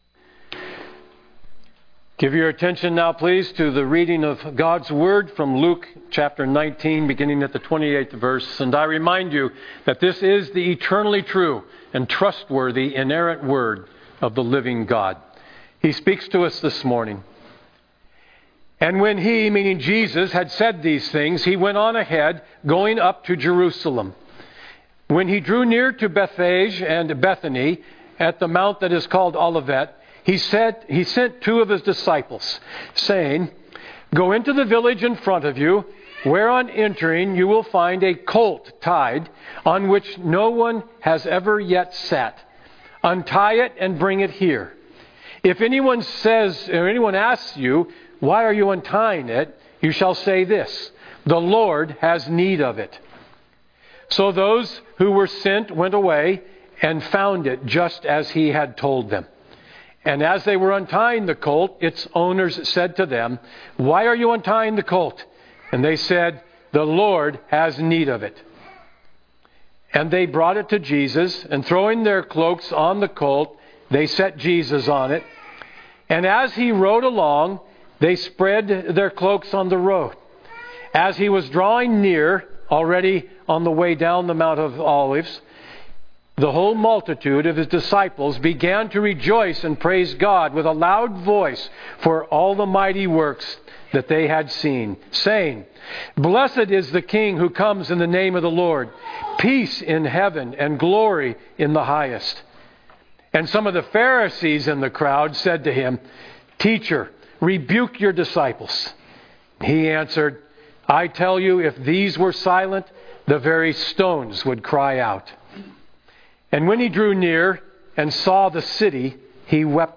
Download Sermon Notes Listen & Download Audio Series